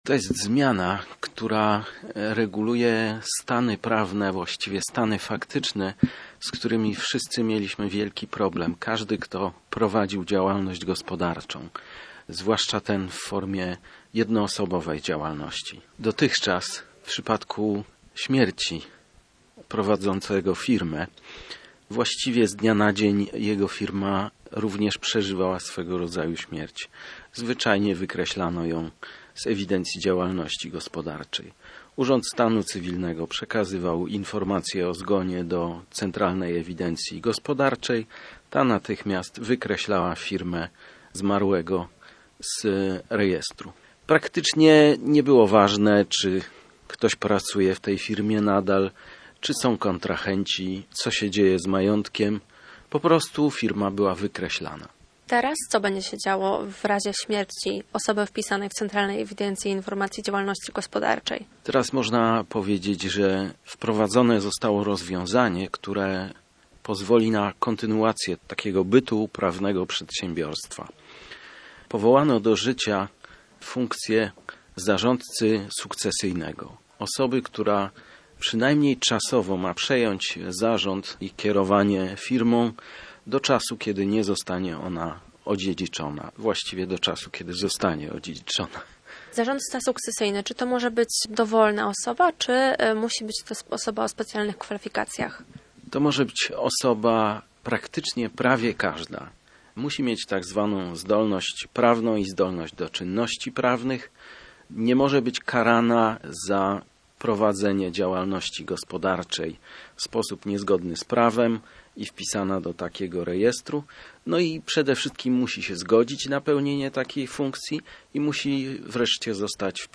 Pod koniec listopada w życie weszły nowe przepisy dotyczące dziedziczenia firm rodzinnych. Na czym polegają zmiany, w rozmowie z